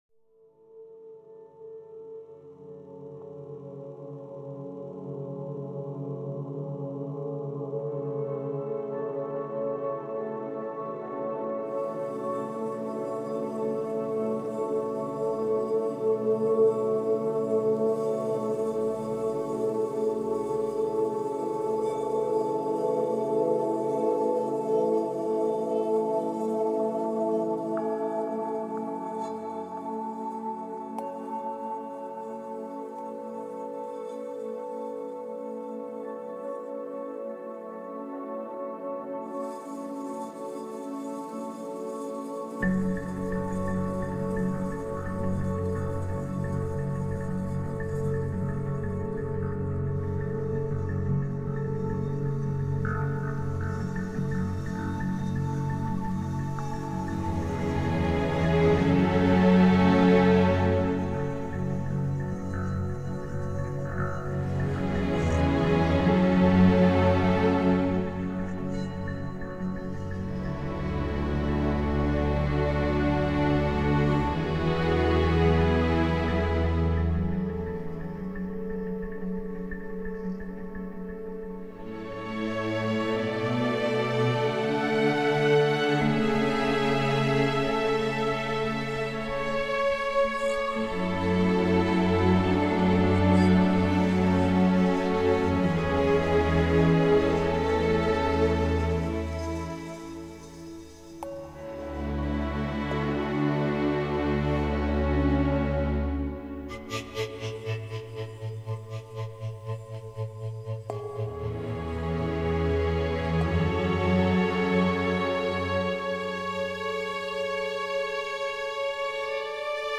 موسیقی بیکلام
موسیقی حماسی